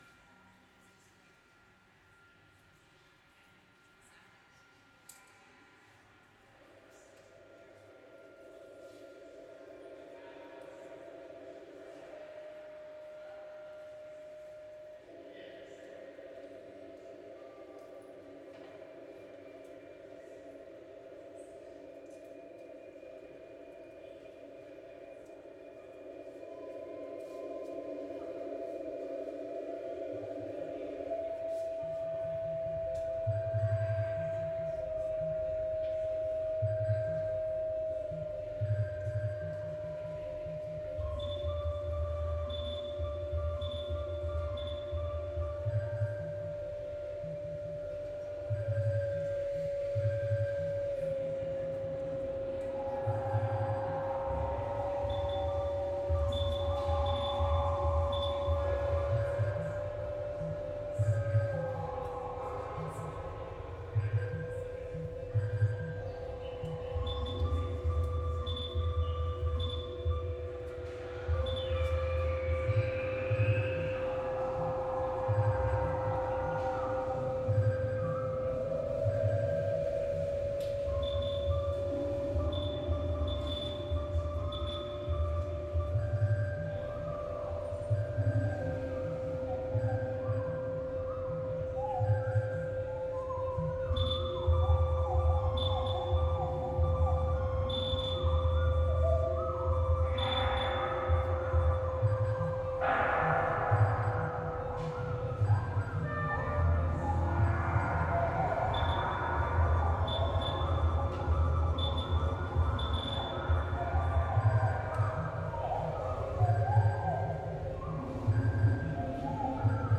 A 60-minute mix of recordings from SPAM New Media Festival which took place at the Georgetown Steam Plant in Seattle in September 2025.
This mix contains excerpts from radio and transmission artworks that were presented at this year’s SPAM as performances and installations.
Their performance is a practice of co-tuning, interference, and spatial resonance; an improvisation with invisible fields — received through software-defined radios, and processed through live electronics and SuperCollider. Grounded in the broken harmony of the Georgetown Steam Plant’s architecture, bodies, machines, and noise converge in a listening practice that resists control and invites uncertainty.